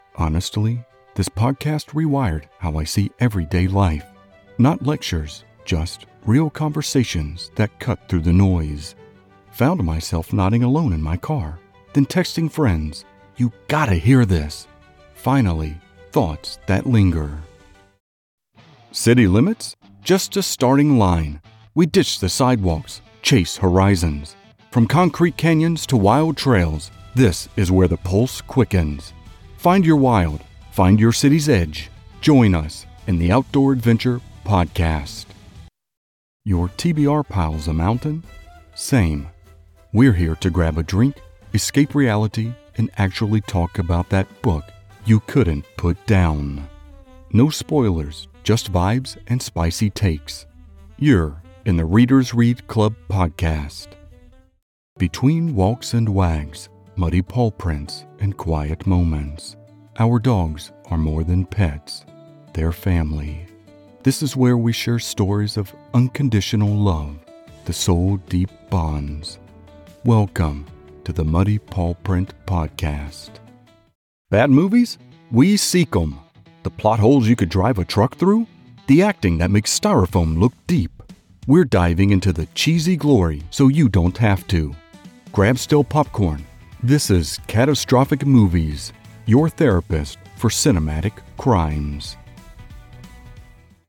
male voiceover artist with a rich, deep, and exceptionally smooth vocal tone.
Podcast Demo
General American, Southern